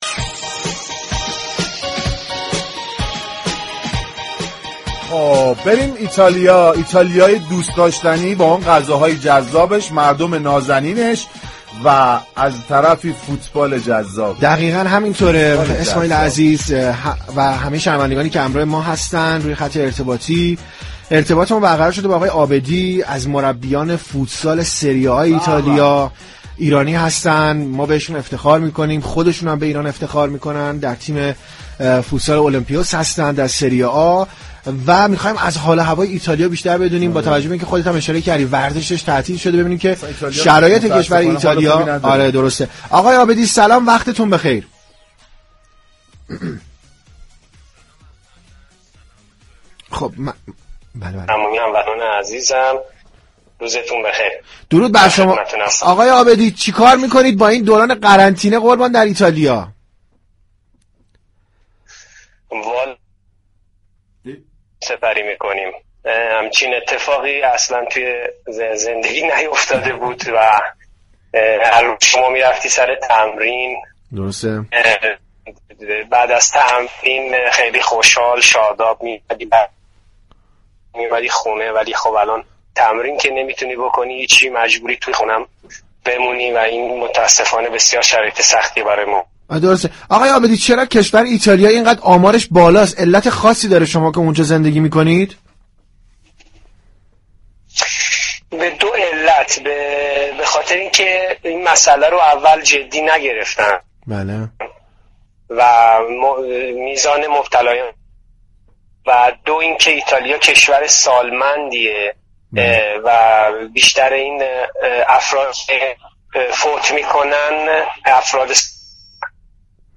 در این راستا، گفتگو با یكی از مربیان تیم فوتسال اولمپیوس سری‌آ در رم ایتالیا، كه از ایرانیان ساكن در این كشور است در این برنامه‌ی رادیویی به صورت زنده انجام شد.